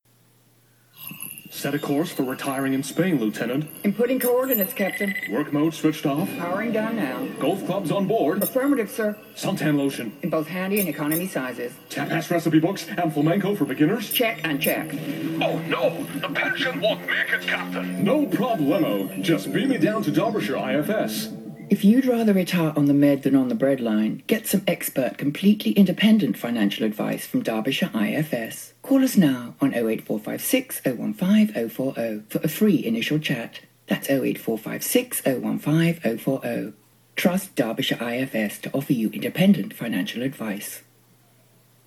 Here's a small selection of financial ads I did at Tangible. 0:41 Derbyshire IFS Star Trek 40" radio ad 0:41 Derbyshire IFS Attenborough 40" radio ad 0:41 Derbyshire IFS Horror 40" radio ad